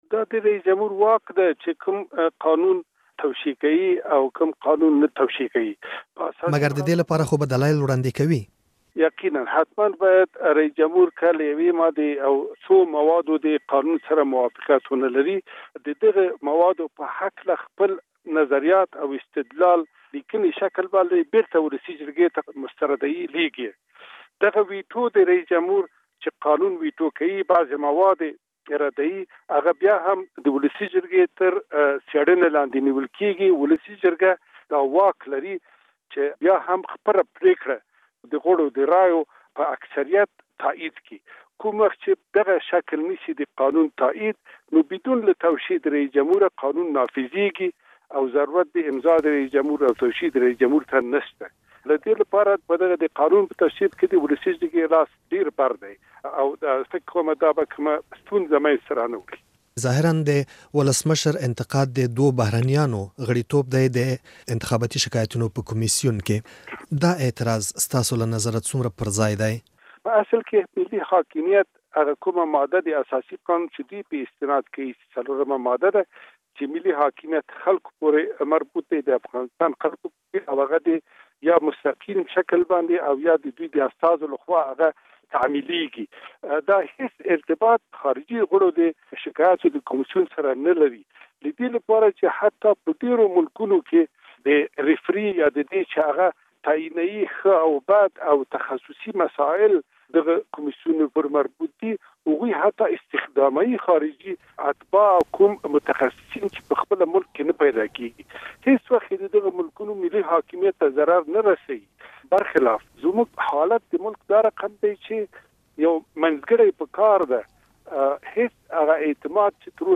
له کبير رنجبر سره مرکه